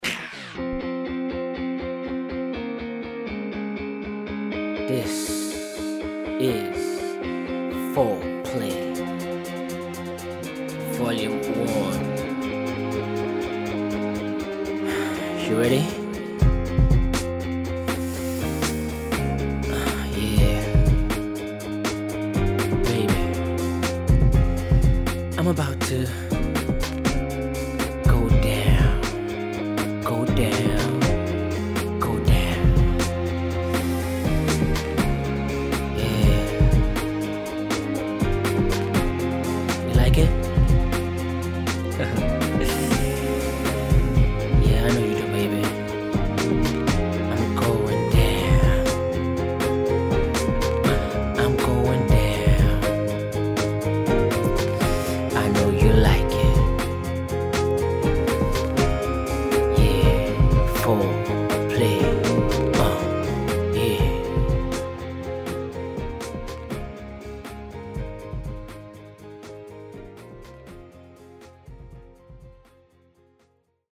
Nigerian Soul and R&B
mixtape